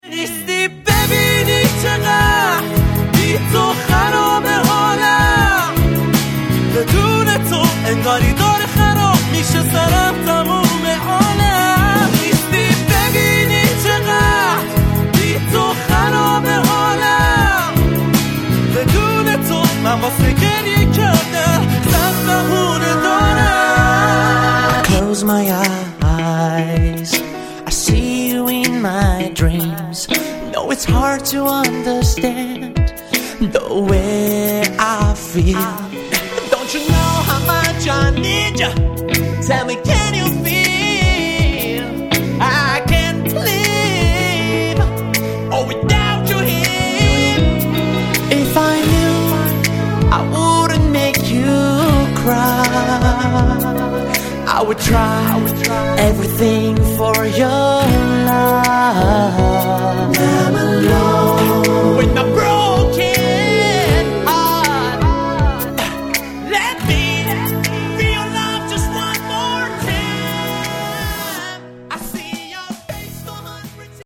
Genres: Indie, Indie Rock
A pop/ R&B song